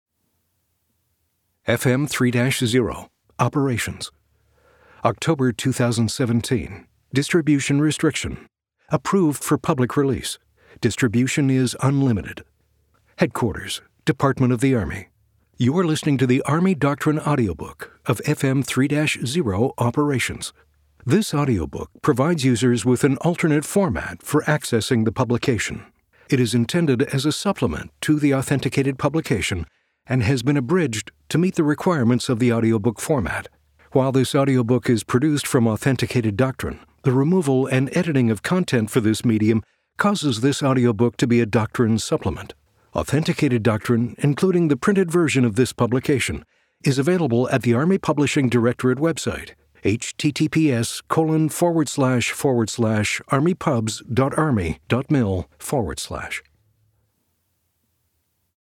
Army Doctrine Audiobook Download Page
The Army Doctrine Audiobook provides users with an alternate format for accessing FM 6-0. It has been abridged to meet the requirements of the audiobook format.